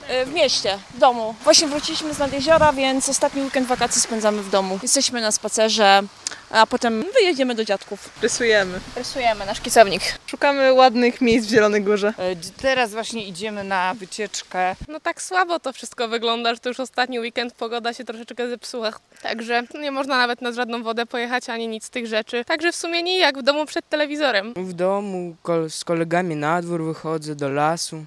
Ostatni weekend wakacji [SONDA]
Jak mówią w rozmowie z nami mieszkańcy, jest to czas przygotowań do szkoły oraz ostatnich, krótkich wycieczek: